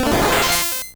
Cri de Stari dans Pokémon Rouge et Bleu.